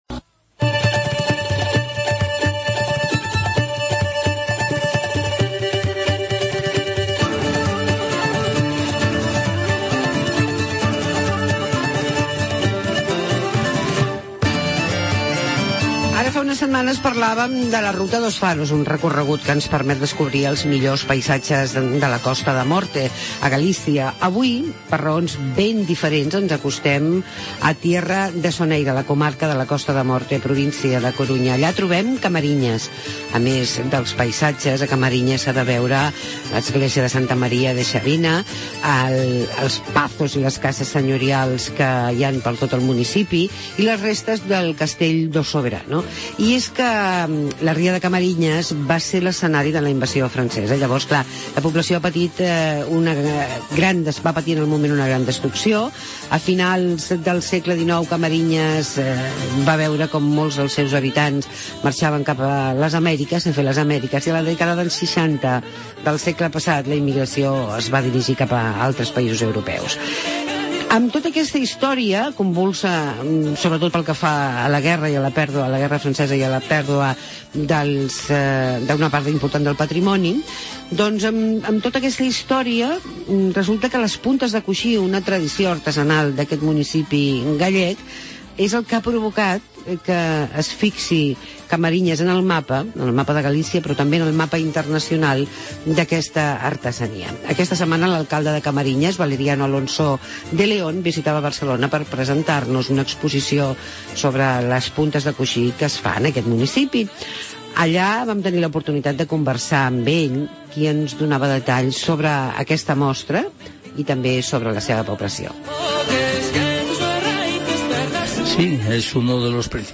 Entrevistamos al alcalde de Camariñas, Valeriano Alonso de Leon